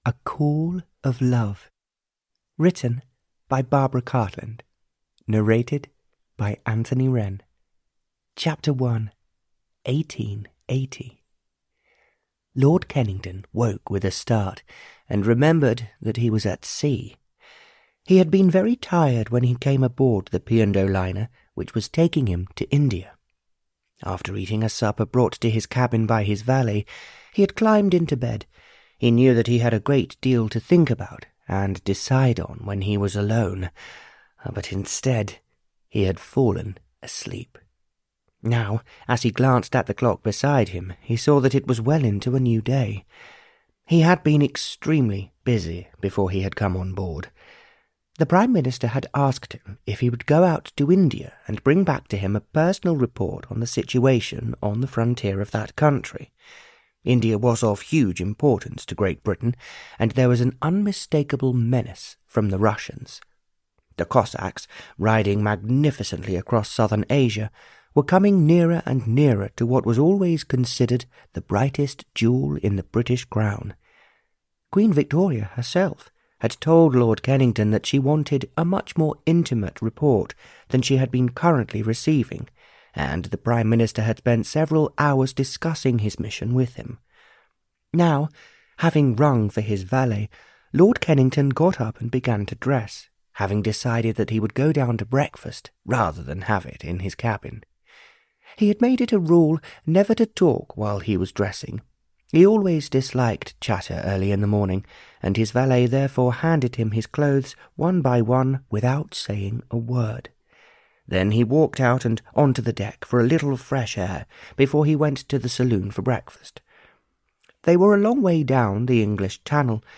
A Call of Love (Barbara Cartland's Pink Collection 101) (EN) audiokniha
Ukázka z knihy